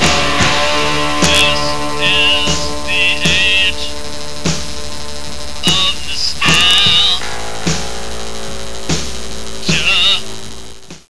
Coming from the golden age of synths
classic digital sound from before digital sound was cool